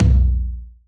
Index of dough-samples/ uzu-drumkit/ lt/